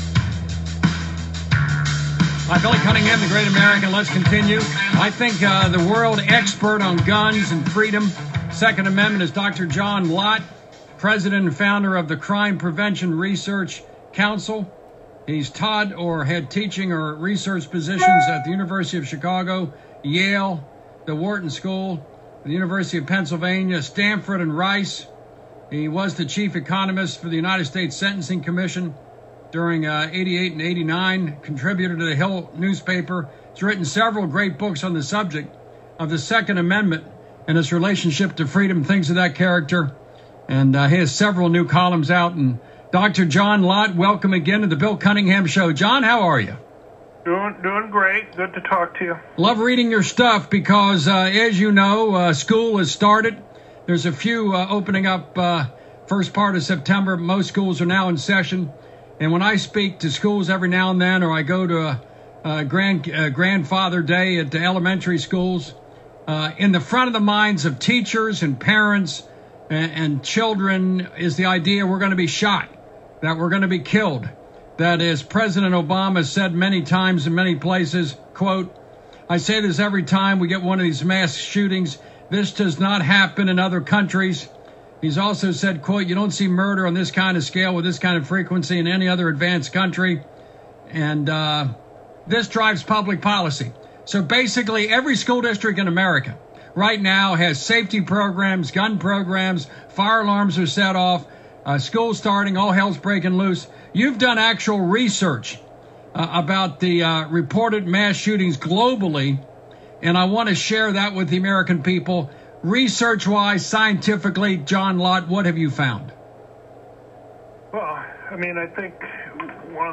Dr. John Lott talked to Bill Cunningham on his Sunday Evening show that is on nearly 350 radio stations. They talked about our new research on mass public shootings around the world.